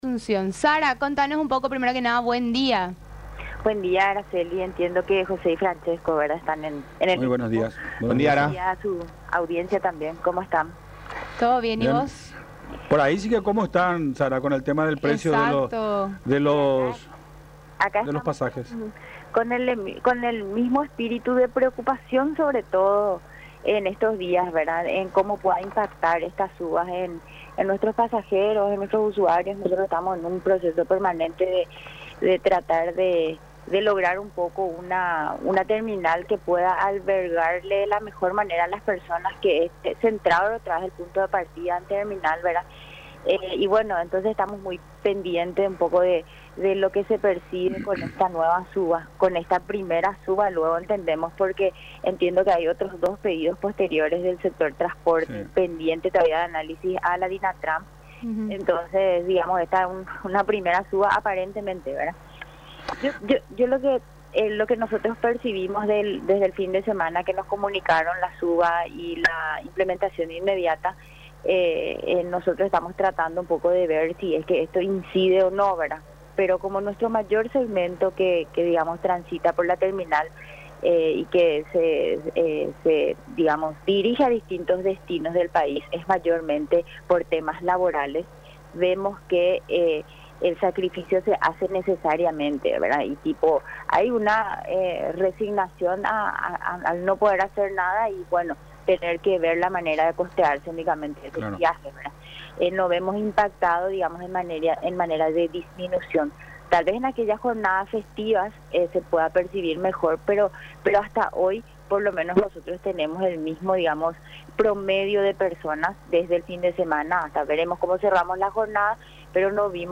en charla con Mañanas Informales por Unión TV